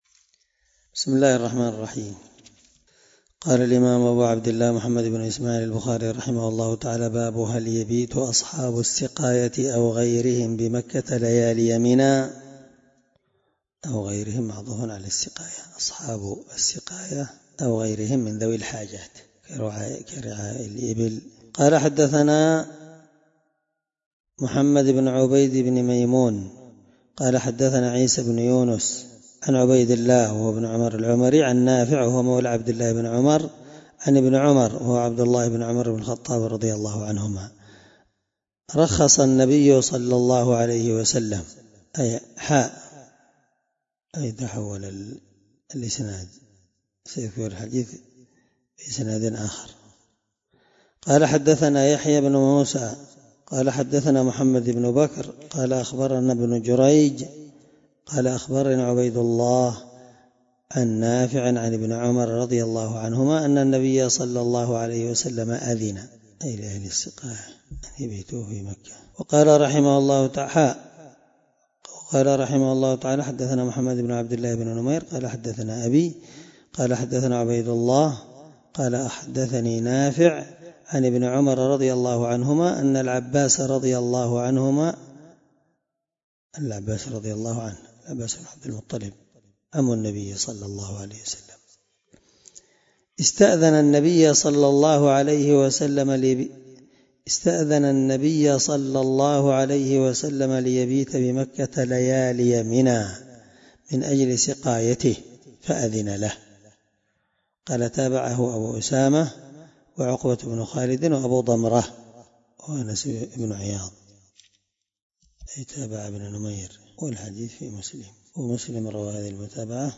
الدرس86 من شرح كتاب الحج حديث رقم(1743-1745 )من صحيح البخاري